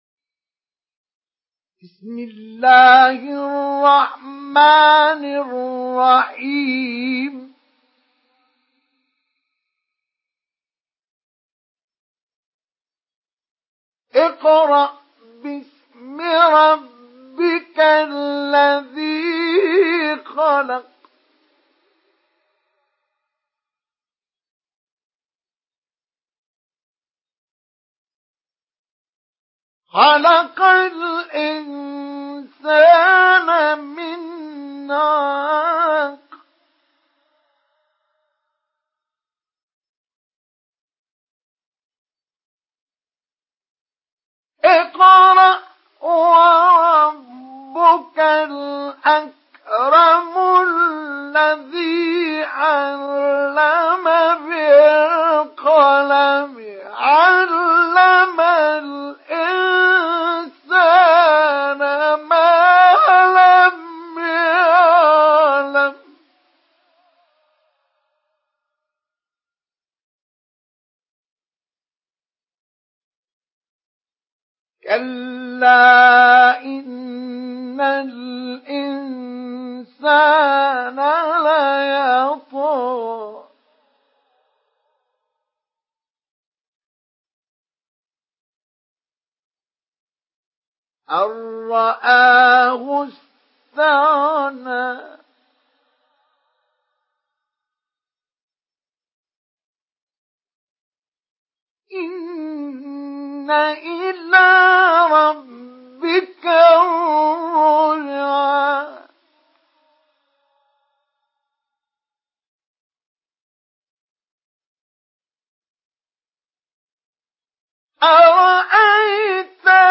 Sourate Al-Alaq MP3 à la voix de Mustafa Ismail Mujawwad par la narration Hafs
Une récitation touchante et belle des versets coraniques par la narration Hafs An Asim.